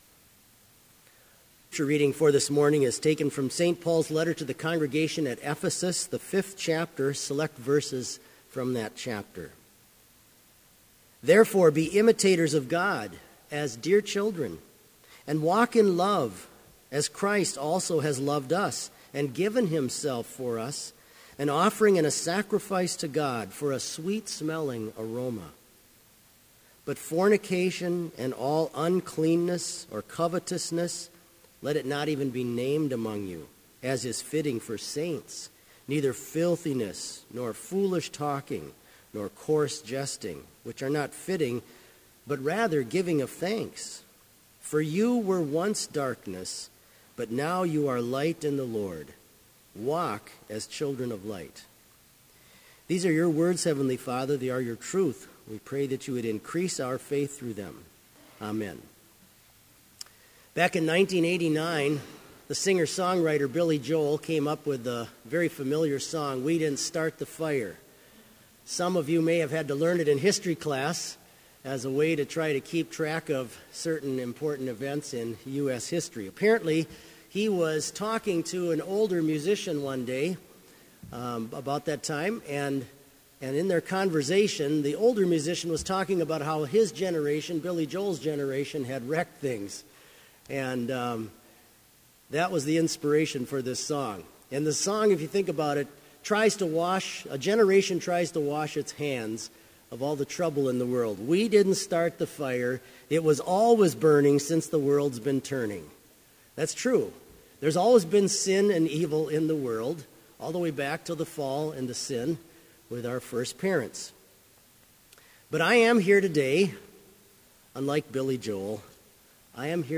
Complete service audio for Chapel - February 26, 2016